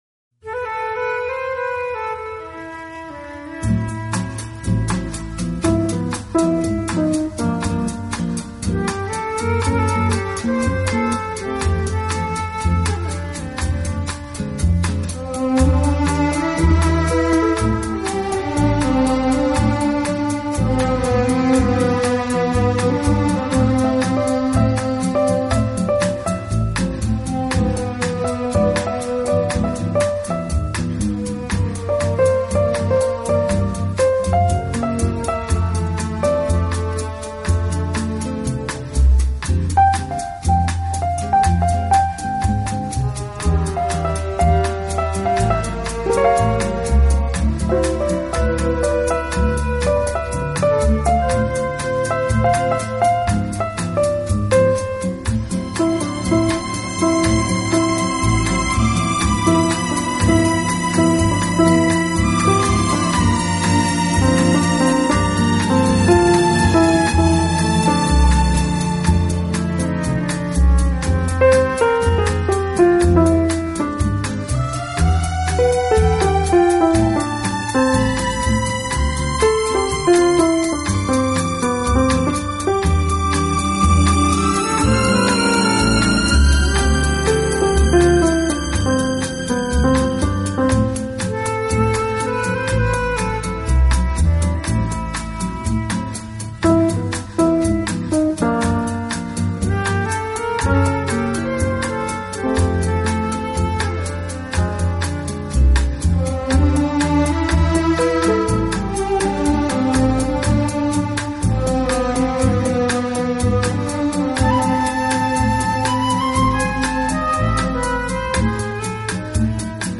Latin Jazz | Bossa Nova
Stereo
巴萨诺瓦的字面意思是「新节奏」，原来是一种拉丁音乐，听起来轻松柔和、慵懒甜美、 浪漫性感。